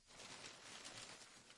努纳维克 " 雪地车启动时嘎嘎作响，运行时摇摇晃晃，关机，假启动
描述：雪地摩托车破裂开始，摇摇欲坠，关闭，误开始
标签： 关闭 雪地摩托 rattly 破碎 摇摇欲坠 启动 运行 关闭
声道立体声